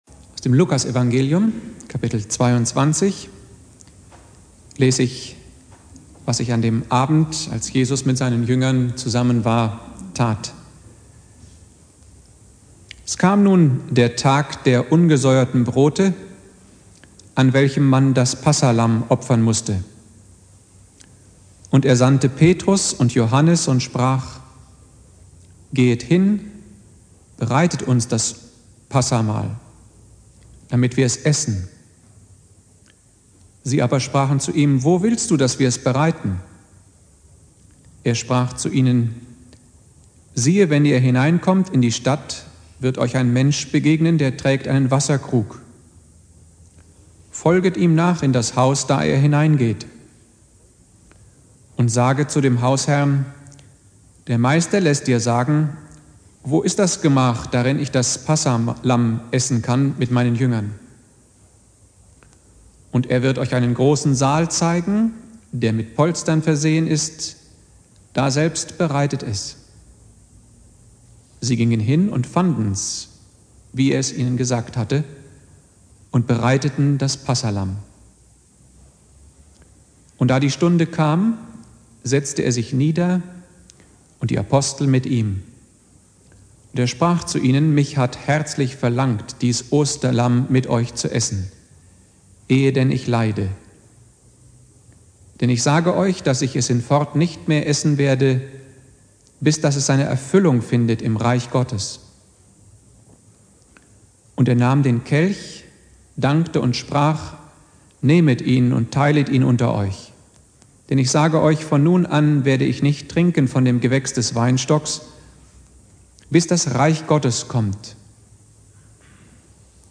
Predigt
Gründonnerstag Prediger